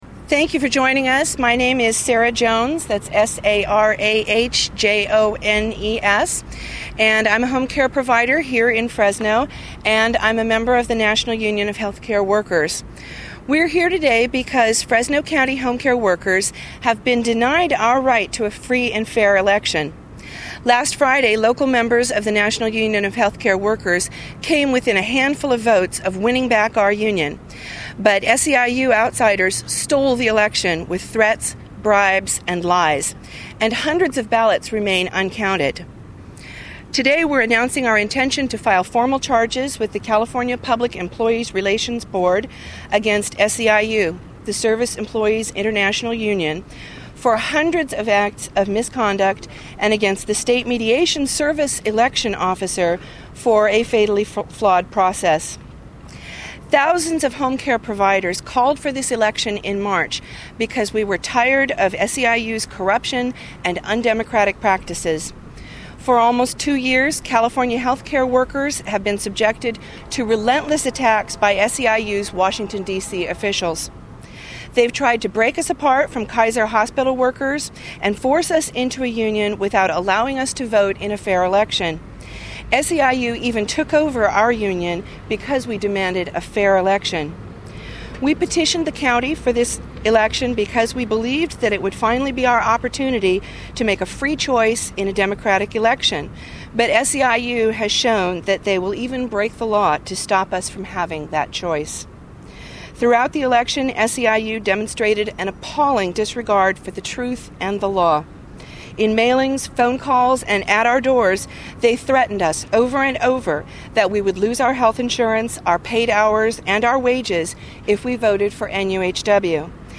§Press Conference
6:53 minute audio of the National Union of Healthcare Workers (NUHW) press conference announcing that they are preparing a legal challenge to the certification of the ballot count and demanding the election be re-run after SEIU’s lawless tactics are investigated.
press_conference.mp3